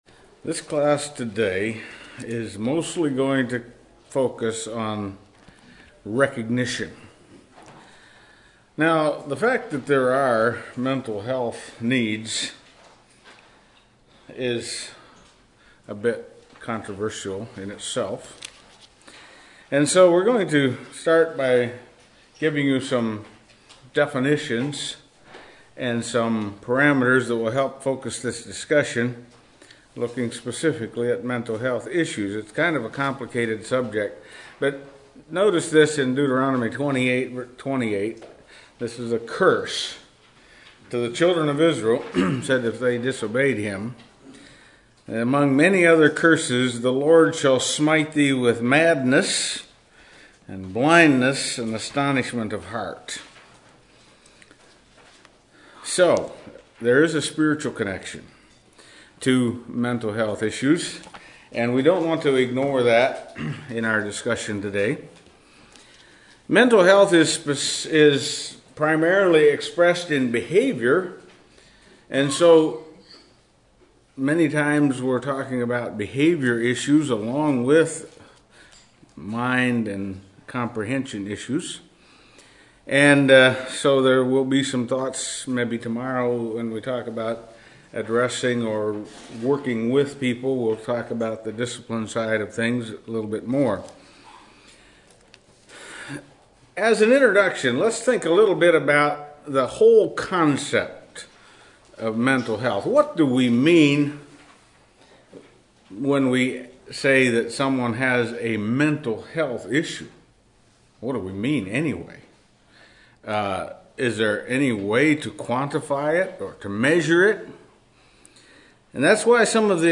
Western Fellowship Teachers Institute 2024